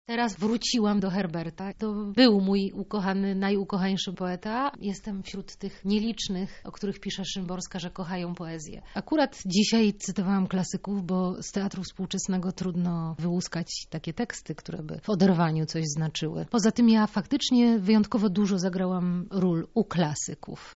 „Spis treści” to comiesięczne rozmowy z aktorami, muzykami i pisarzami o literaturze.